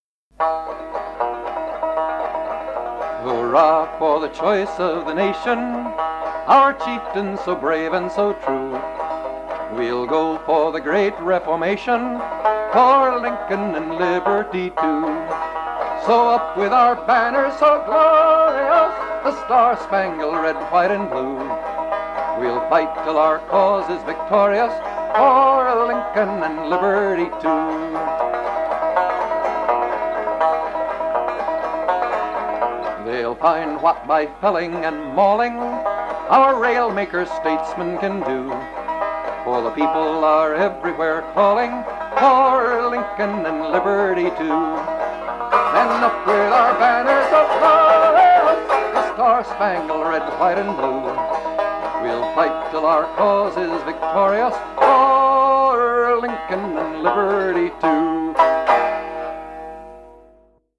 This song is in 3/4 waltz time.